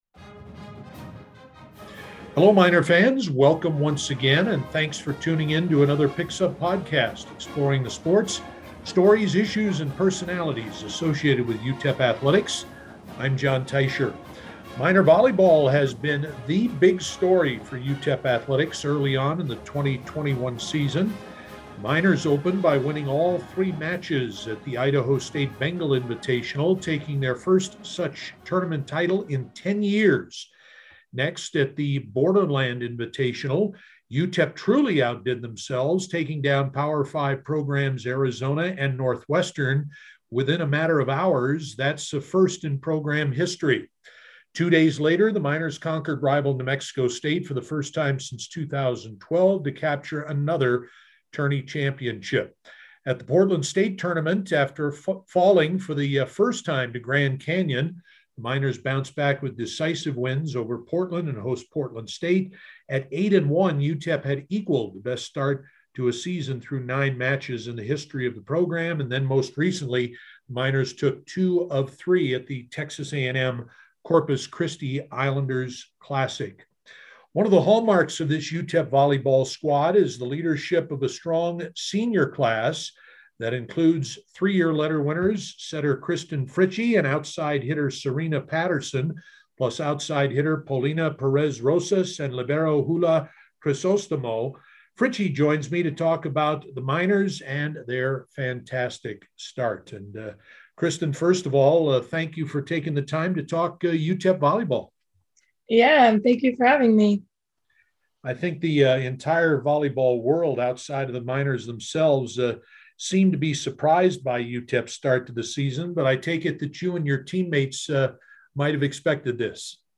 visits with the senior setter who has been instrumental in the team's 10-2 start.